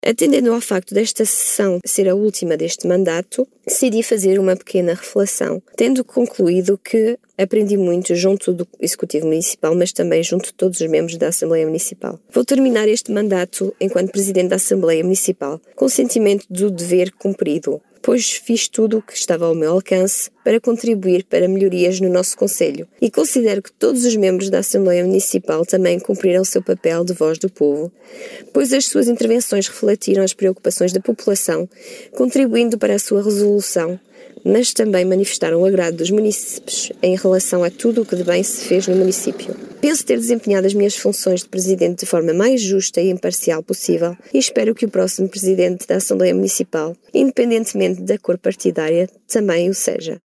Realizou-se na manhã desta terça-feira, 30 de setembro, no Salão Nobre da Câmara Municipal de Vila Nova de Paiva, a Assembleia Municipal relativa ao mês de setembro.
Em declarações à Alive FM, destacou o trabalho desenvolvido ao longo dos últimos anos e agradeceu a colaboração de todos os membros da Assembleia, assim como o apoio da comunidade.